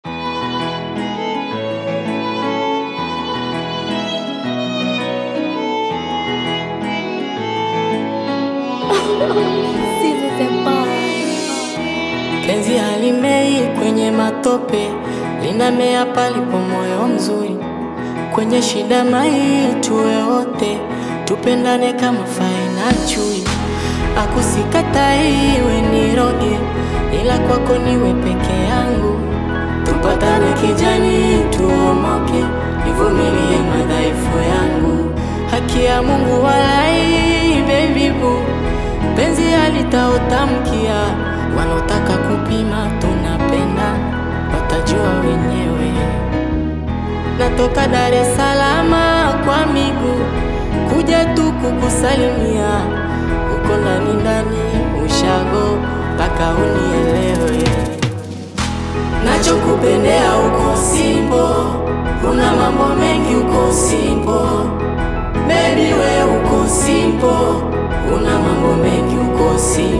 an emerging talent in the Tanzanian Bongo Flava scene
vibrant and melodious compositions